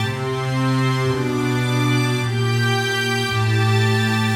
Index of /musicradar/80s-heat-samples/110bpm
AM_80sOrch_110-C.wav